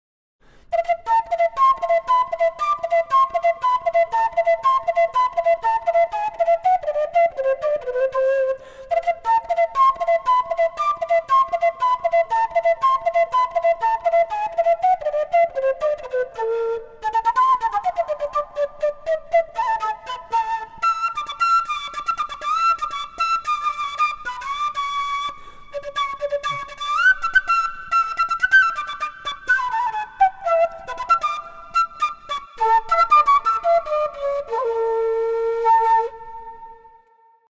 Кена G (пластик)
Кена G (пластик) Тональность: G
Бюджетная модель кены, изготовленная из ПВХ-трубы. Несмотря на простоту исполнения имеет вполне ровный строй и неприхотлива в использовании и хранении.